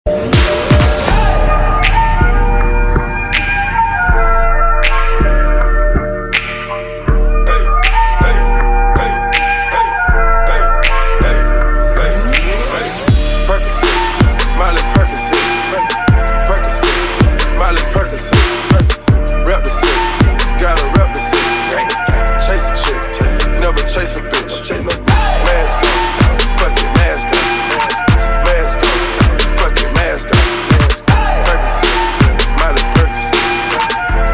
hip-hop track
features a prominent flute sample